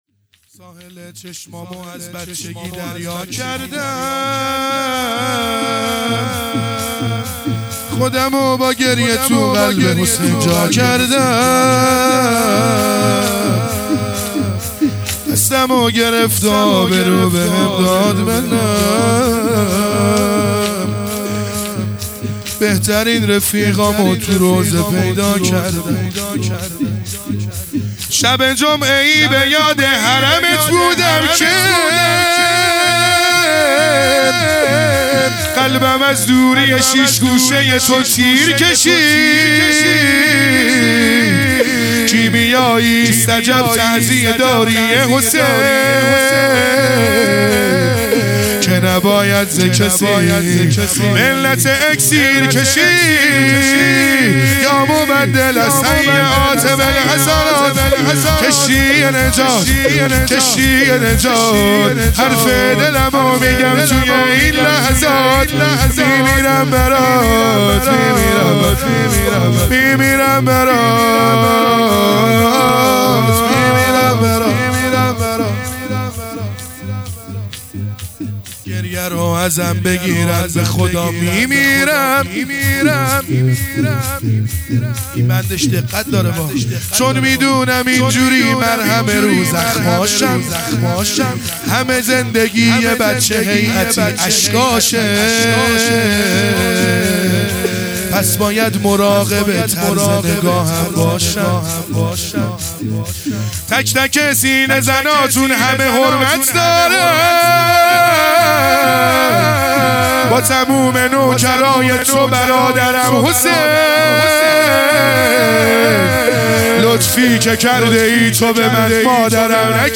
شهادت حضرت ام البنین (س) | 22دی ماه1400 | شورپایانی